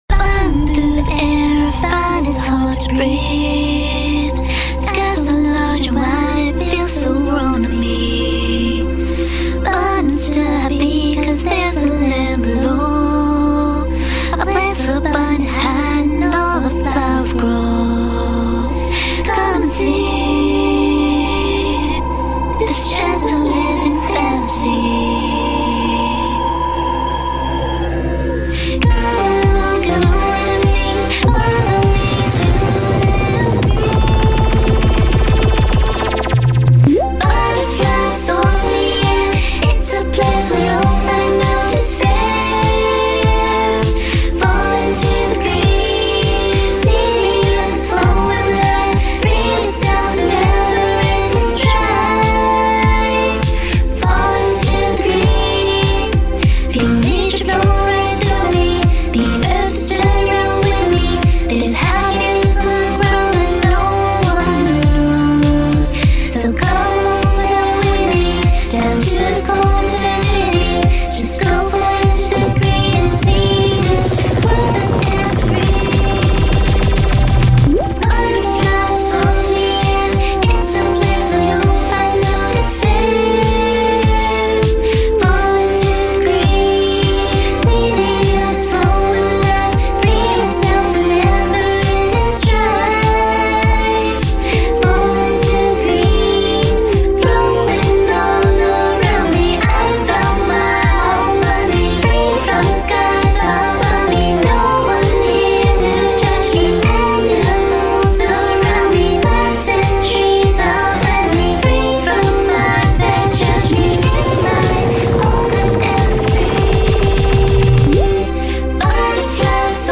It's a tad bit repetitive but whatever, I guess.